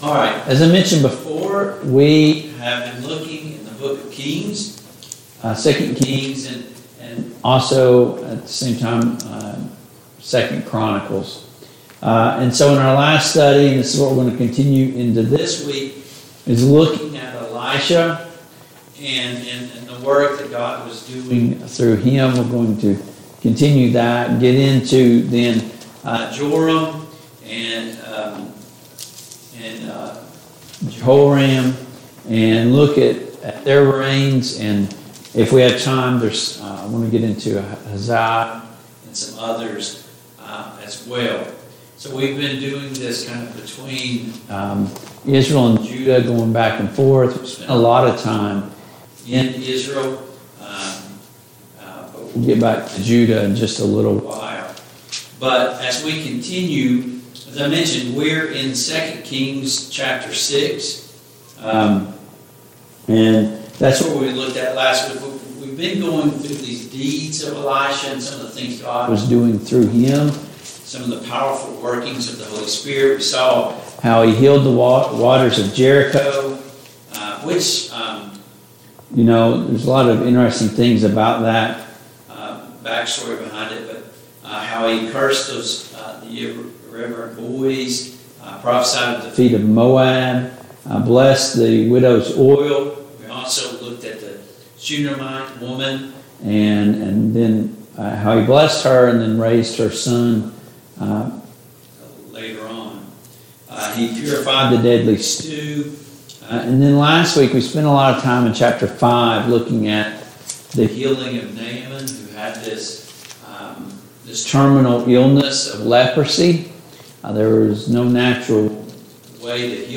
The Kings of Israel Passage: 2 Kings 6, 2 Kings 7, 2 Kings 8 Service Type: Mid-Week Bible Study Download Files Notes « 3.